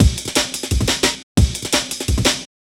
futur_amen.wav